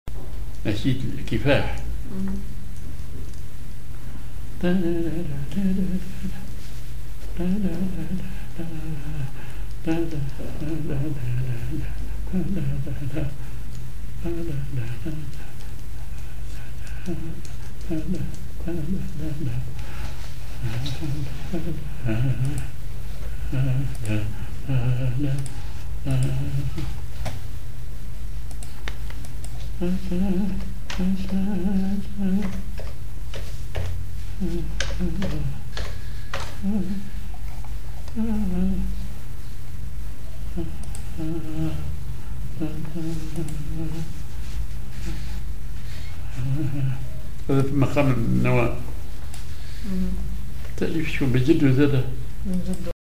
Maqam ar نوا
Rhythm ar برول
genre نشيد